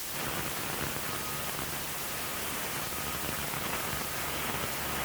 ttr_s_ara_csa_staticLoop.ogg